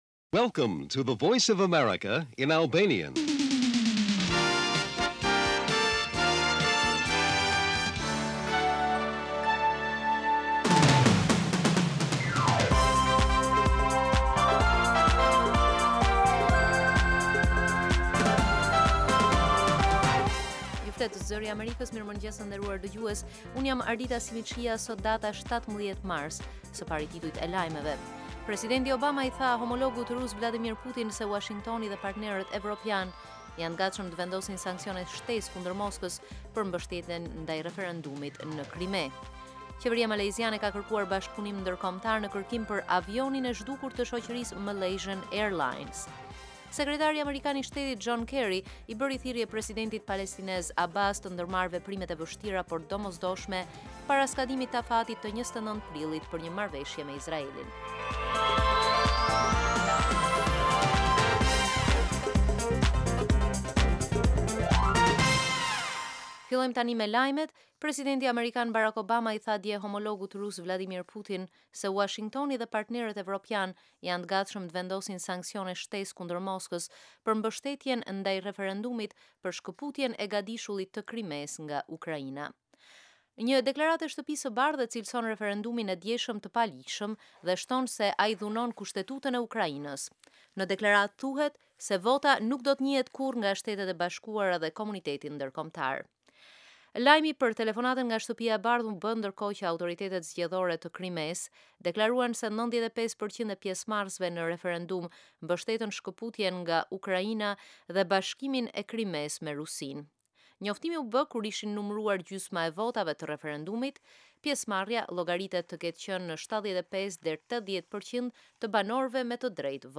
Lajmet e mëngjesit